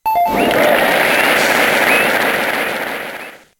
correctNoMusic.ogg